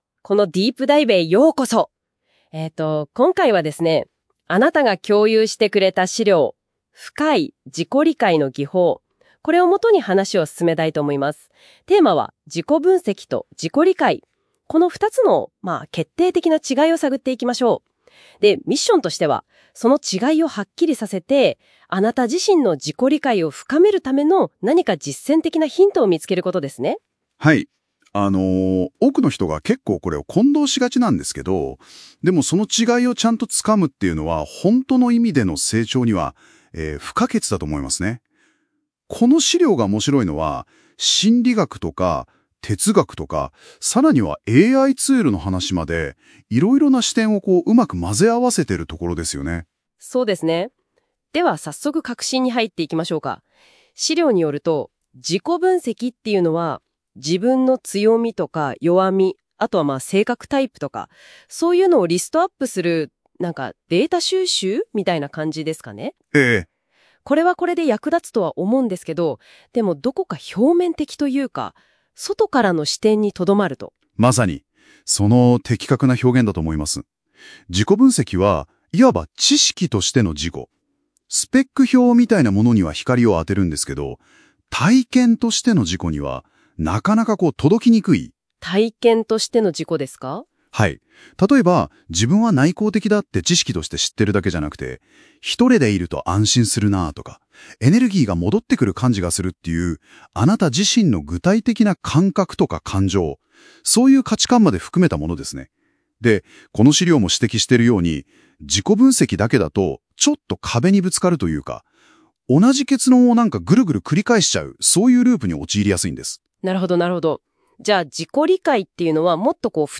音声解説